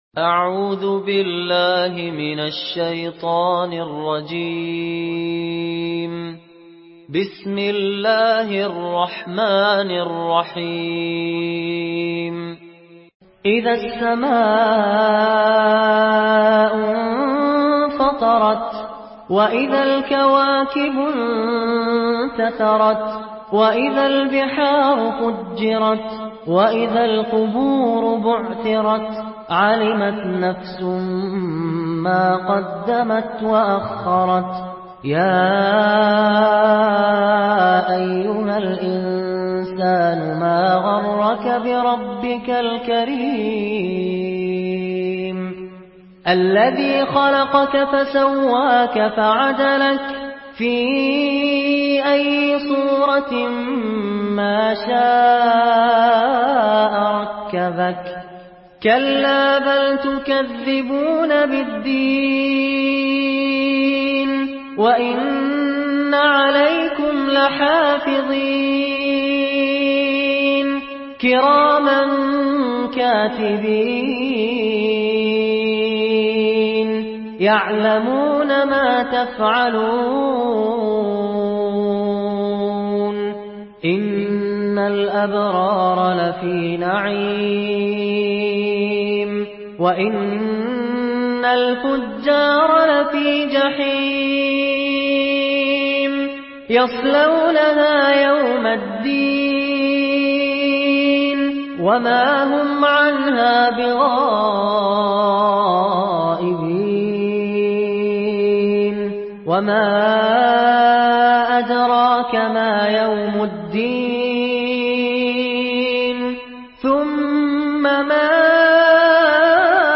Surah Infitar MP3 by Fahad Alkandari in Hafs An Asim narration.
Murattal